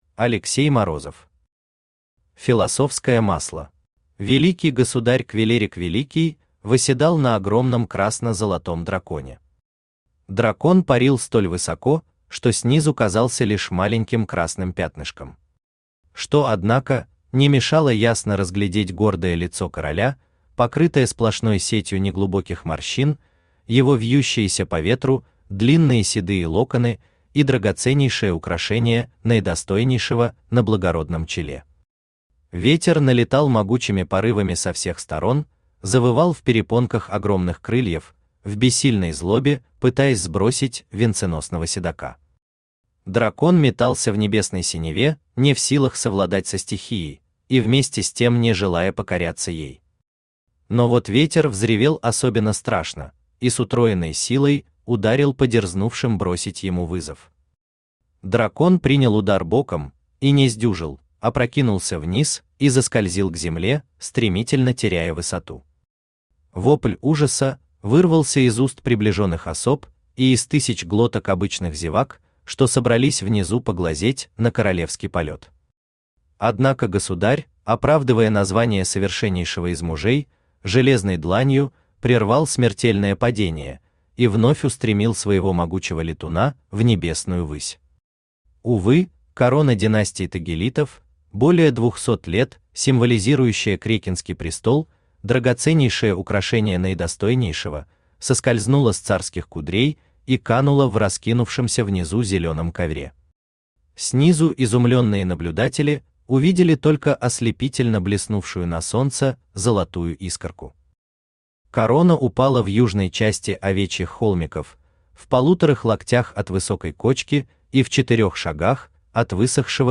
Аудиокнига Философское Масло | Библиотека аудиокниг
Aудиокнига Философское Масло Автор Алексей Борисович Морозов Читает аудиокнигу Авточтец ЛитРес.